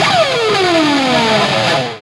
GTR WOW S02L.wav